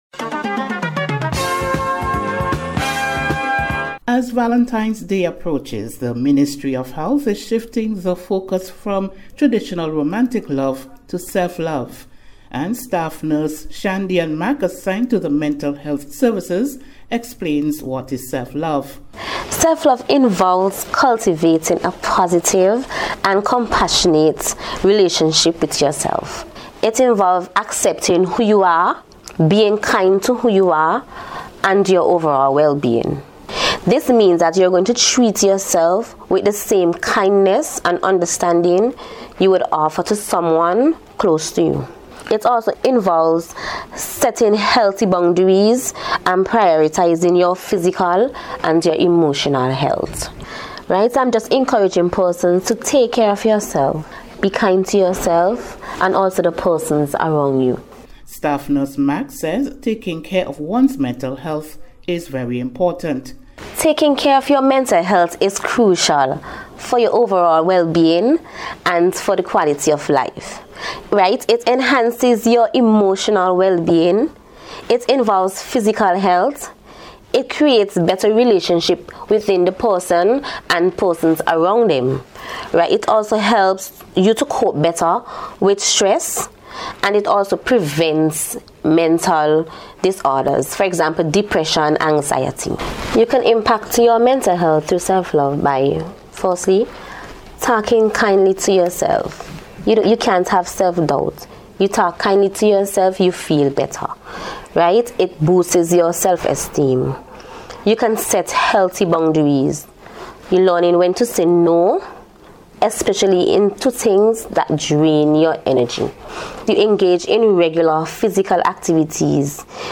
NBC’s Special Report- Thursday 12th February,2026